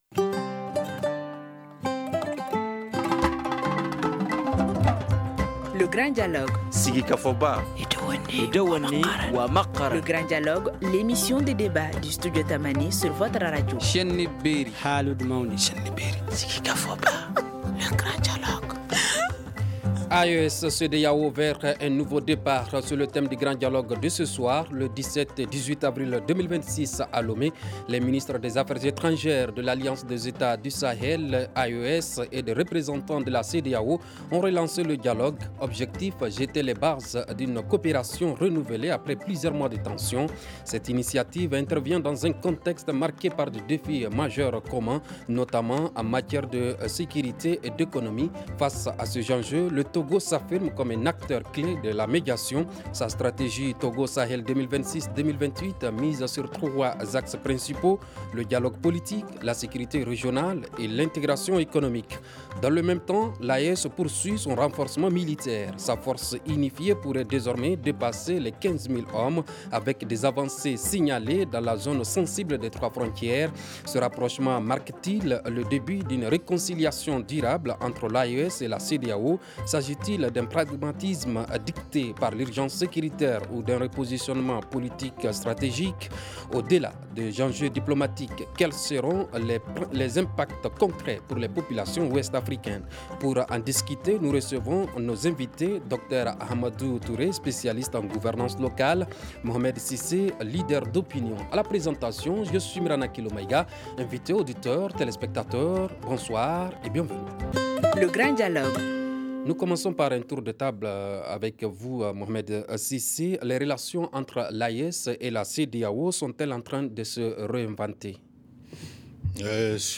Pour en discuter, nous recevons nos invités.